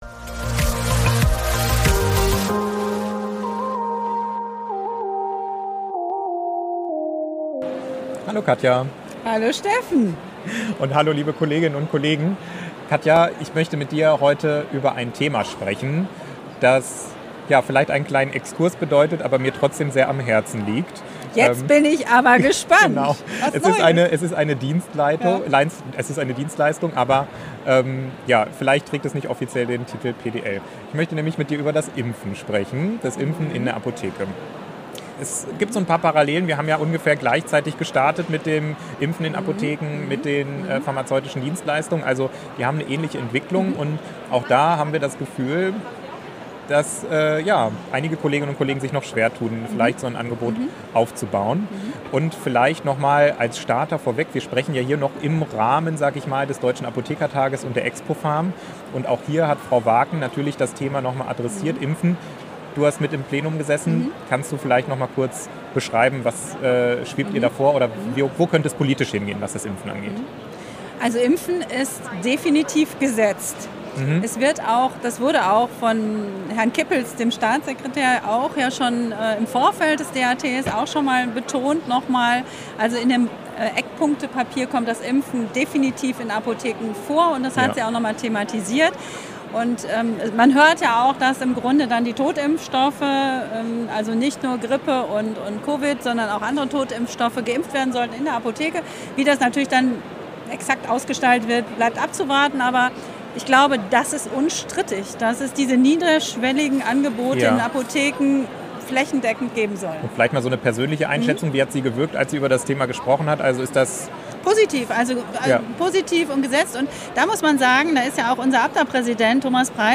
Live von der expopharm: So gelingt das Impfen in der Apotheke